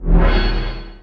锣.wav
乐器类/锣.wav